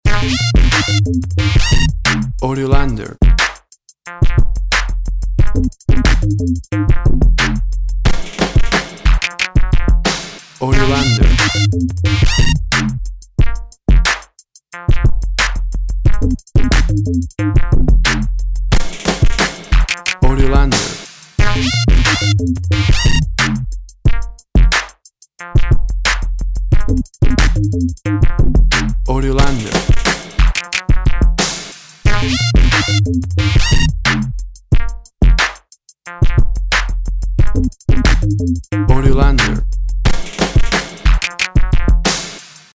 WAV Sample Rate 16-Bit Stereo, 44.1 kHz
Tempo (BPM) 90